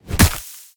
File:Sfx creature pinnacarid hop fast 02.ogg - Subnautica Wiki
Sfx_creature_pinnacarid_hop_fast_02.ogg